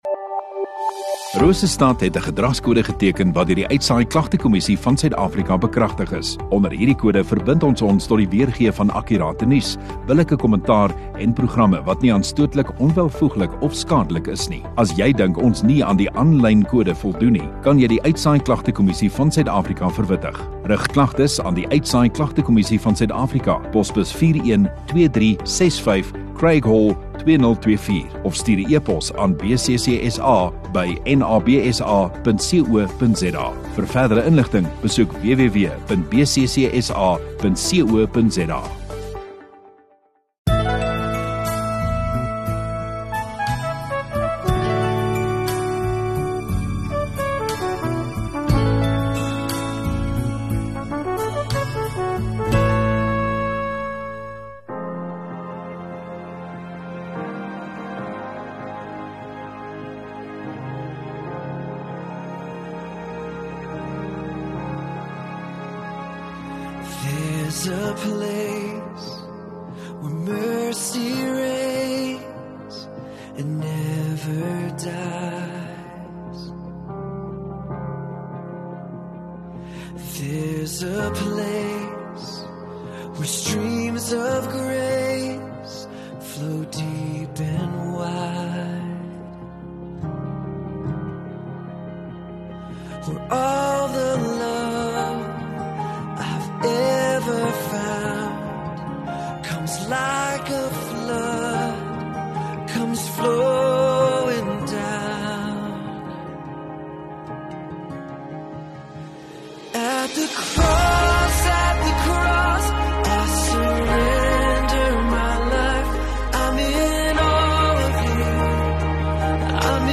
24 Aug Sondagaand Erediens